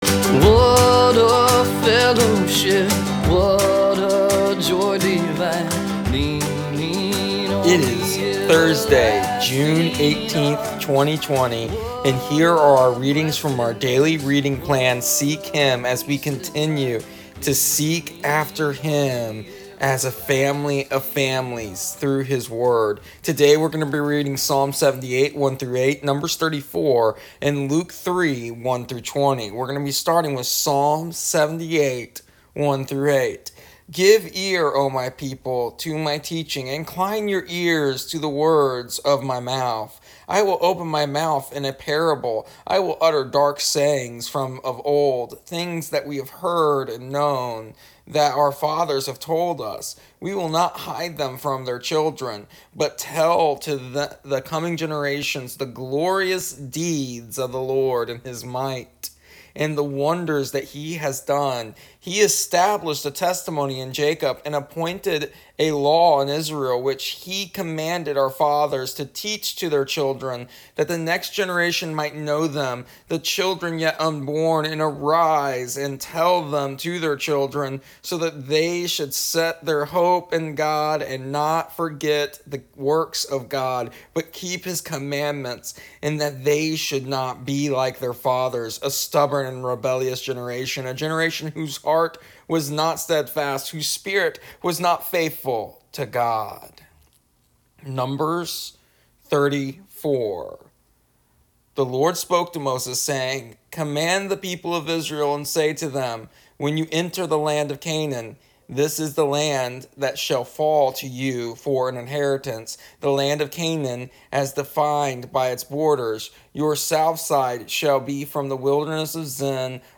With this being said, here is an audio version of our daily readings from our daily reading plan Seek Him for June 18th, 2020.